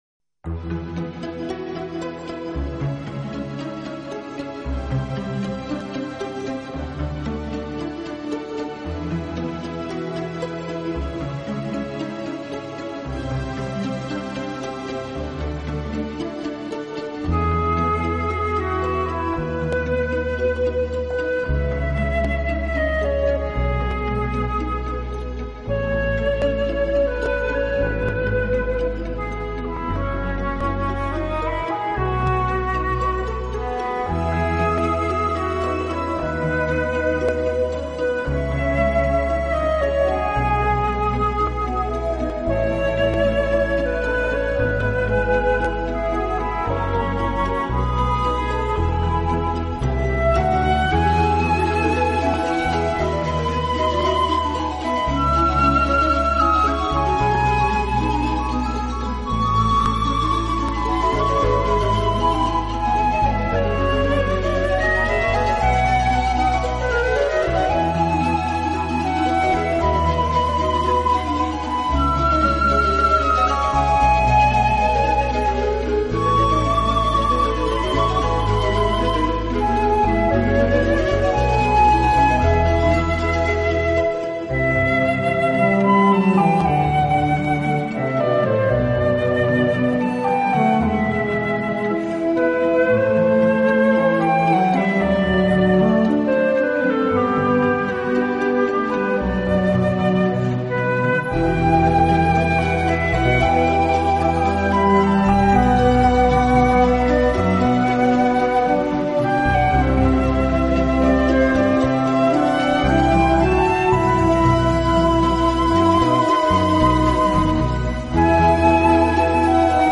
Genre................: Classical